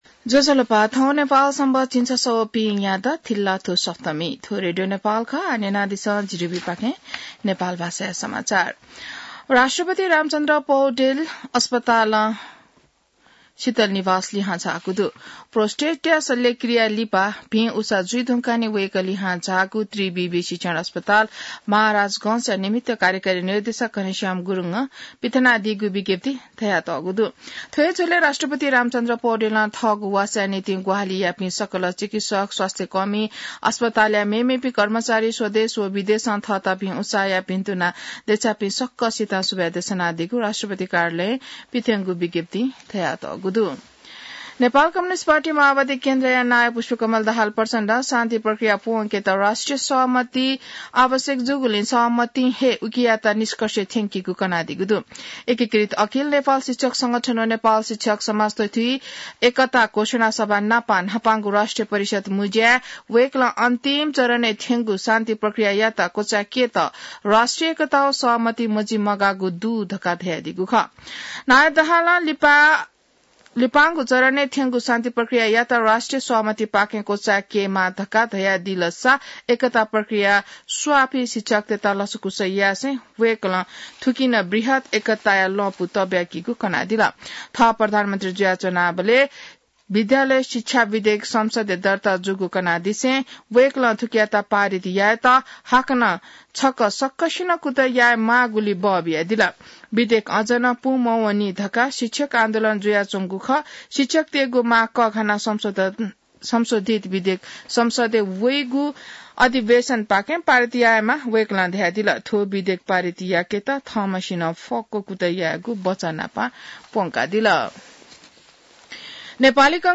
नेपाल भाषामा समाचार : २४ मंसिर , २०८१